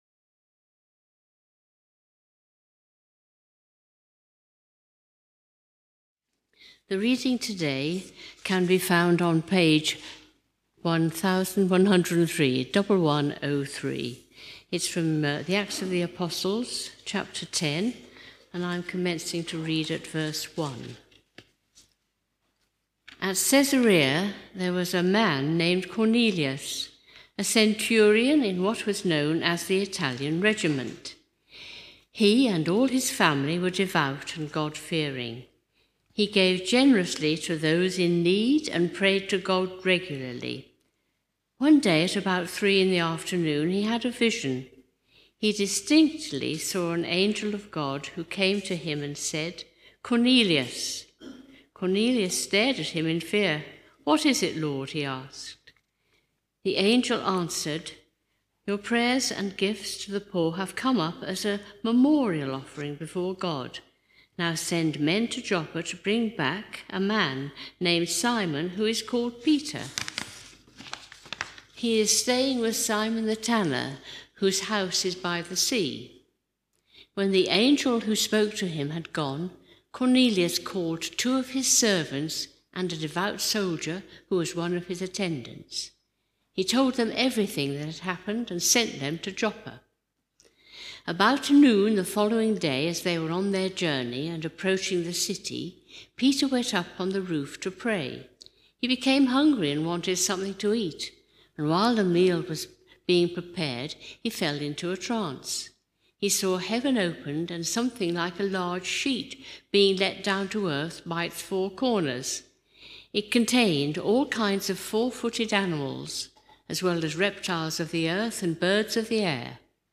Our apologies for some technical problems in today’s recording.